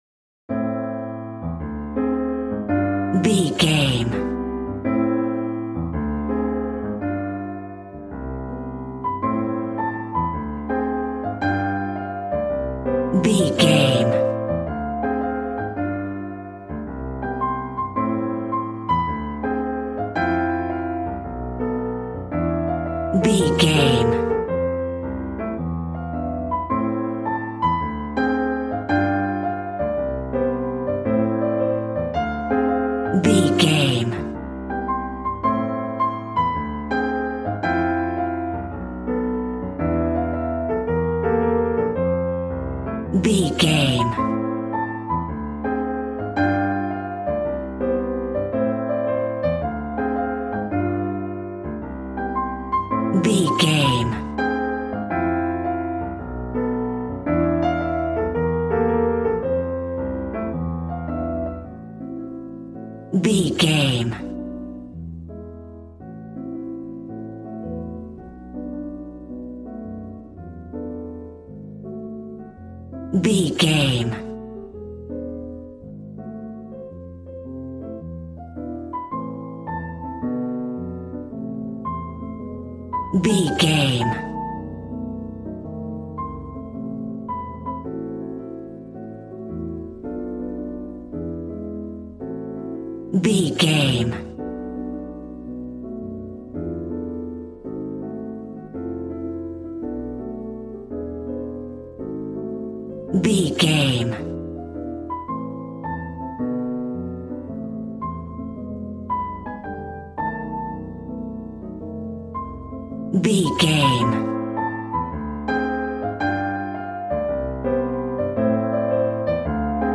Aeolian/Minor
smooth
piano
drums